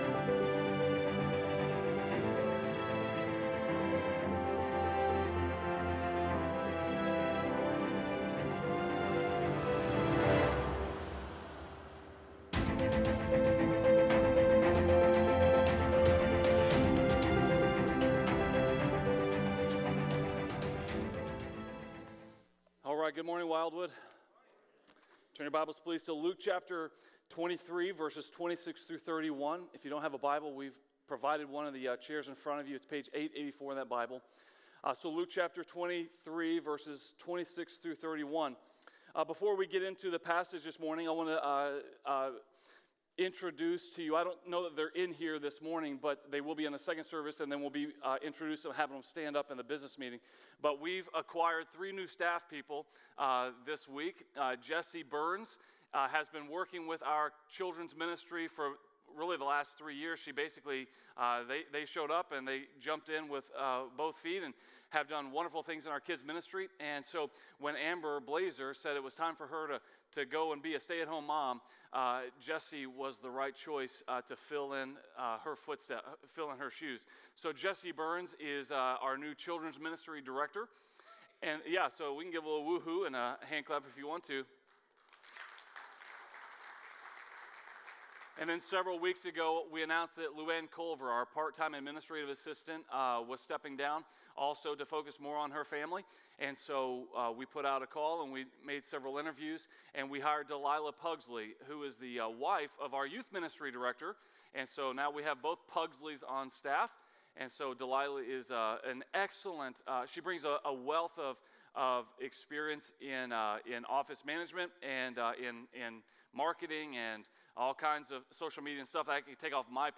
A message from the series "Relationships 101." Forgiveness might be the most difficult part of relationships!